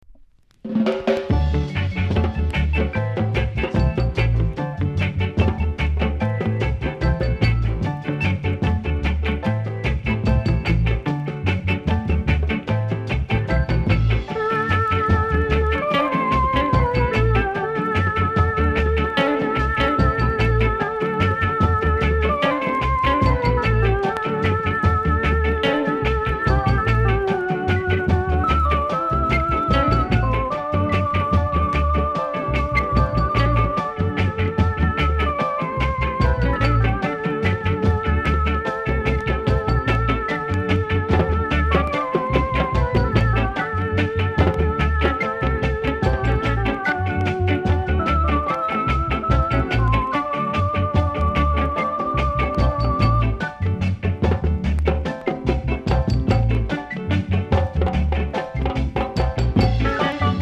※裏面の後半に極小さな紙の噛み込みあり、僅かにノイズを拾います。